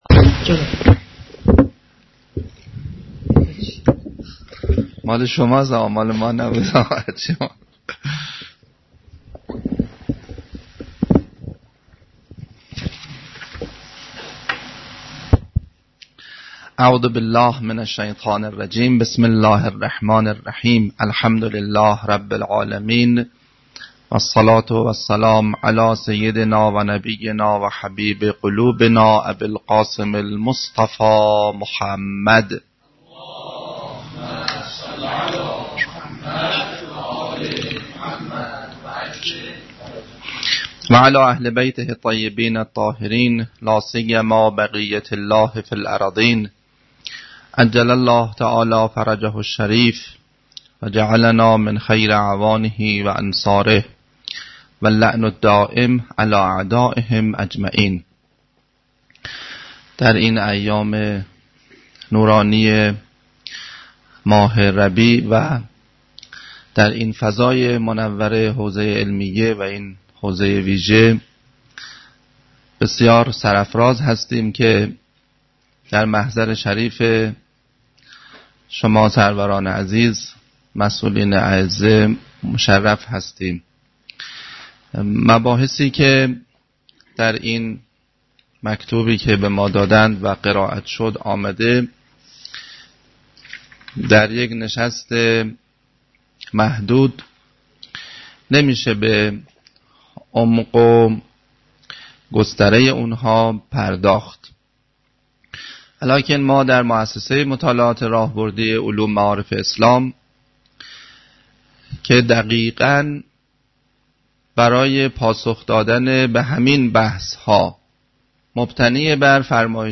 پنجمین نشست همایش تبیین نسبت حوزه و انقلاب